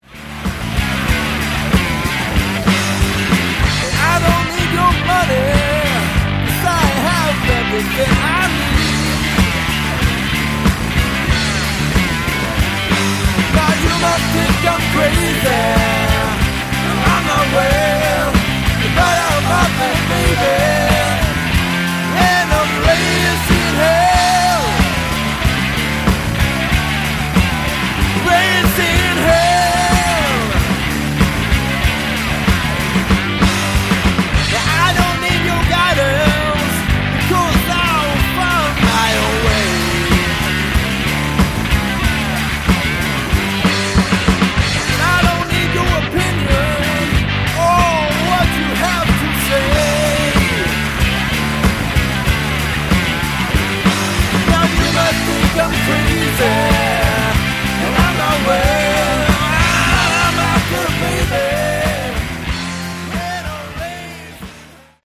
Category: Blues Rock
lead guitars
lead vocals, harmonica, rhythm guitar
drums
bass, backing vocals